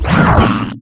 alianhit3.ogg